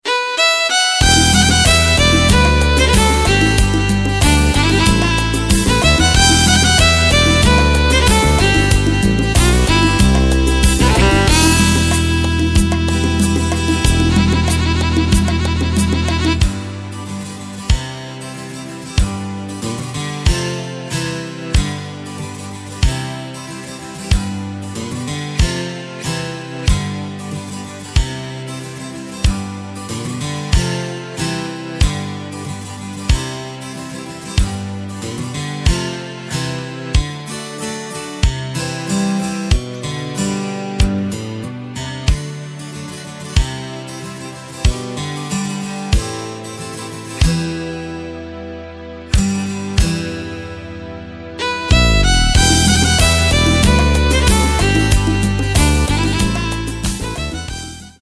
Takt: 2/4 Tempo: 93.50 Tonart: G
mp3 Playback Demo